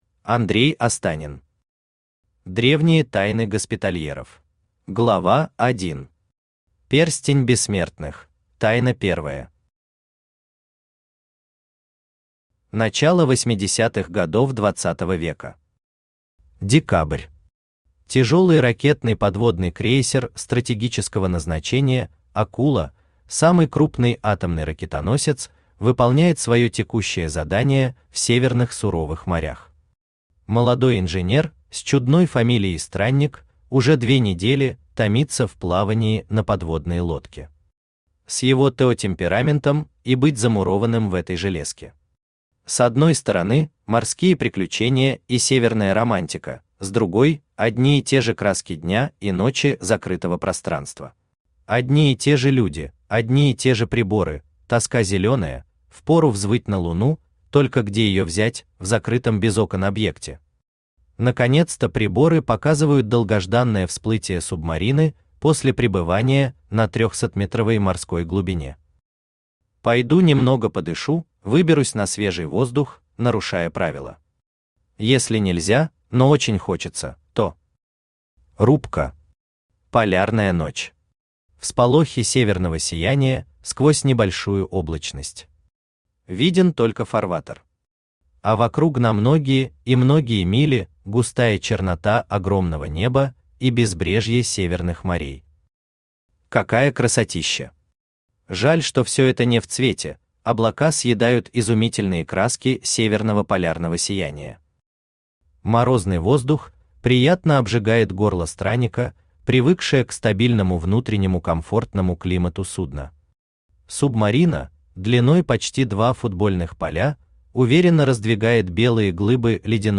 Aудиокнига Древние тайны госпитальеров Автор Андрей Леонидович Останин Читает аудиокнигу Авточтец ЛитРес.